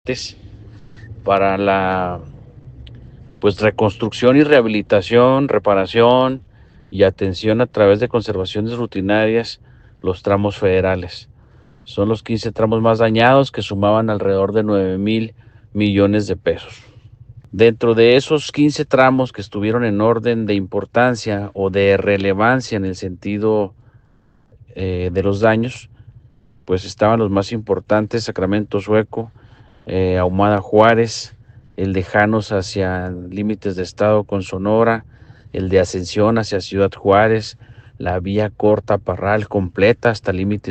AUDIO: JORGE CHÁNEZ, SECRETARÍA DE COMUNICACIONES Y OBRAS PÚBLICAS (SCOP)